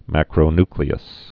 (măkrō-nklē-əs, -ny-)